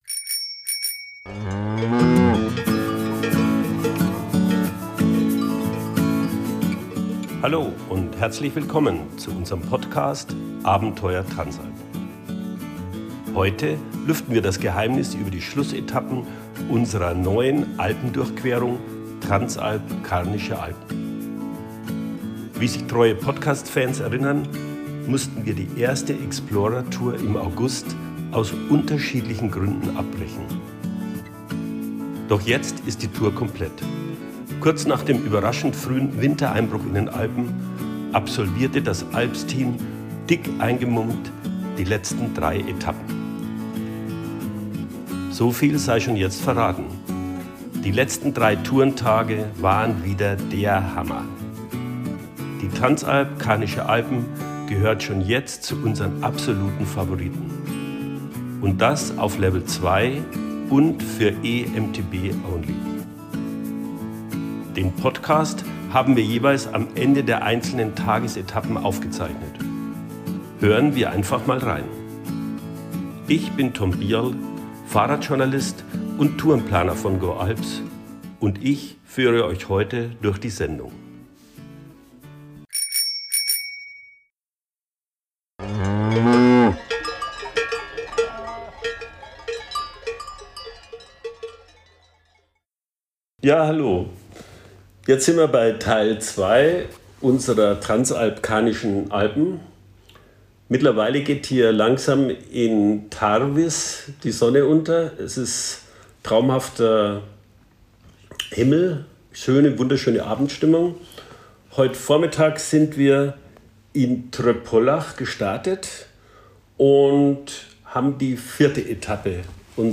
In dieser Episode unseres Podcast „Abenteuer Transalp“ seid ihr „live“ beim 2. Teil der Explorer Tour „Transalp Karnische Alpen“ dabei.
Der Podcast begleitet die ALPS Guides bei der exklusiven Explorer Tour.